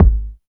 85 KICK 2.wav